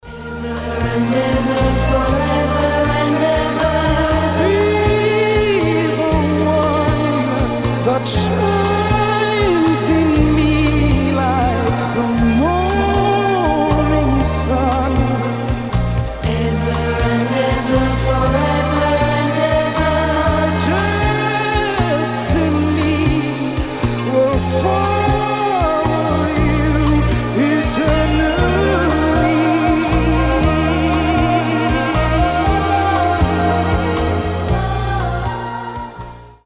• Качество: 320, Stereo
дуэт
70-е